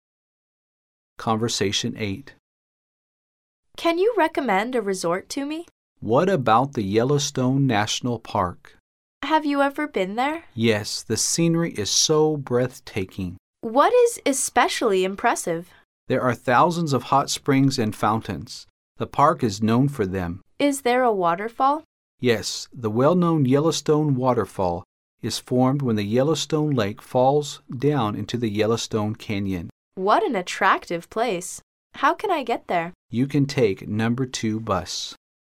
Conversation 8